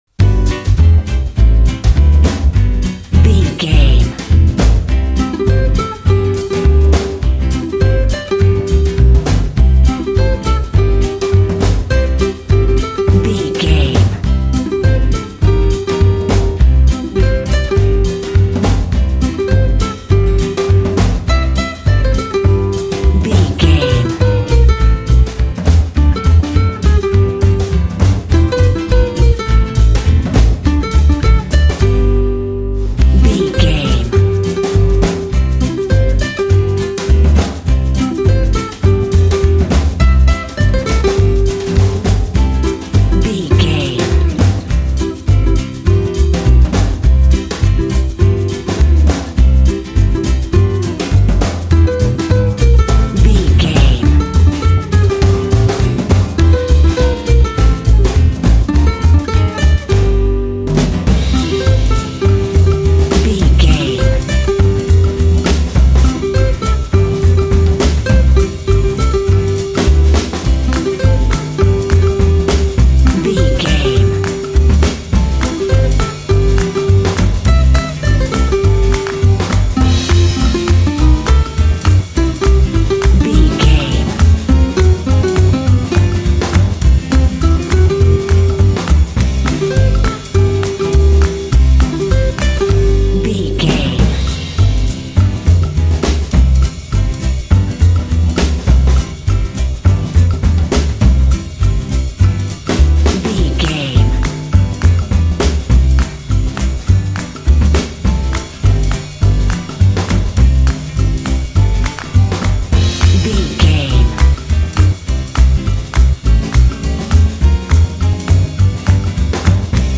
Ionian/Major
light
playful
uplifting
calm
cheerful/happy
drums
bass guitar
acoustic guitar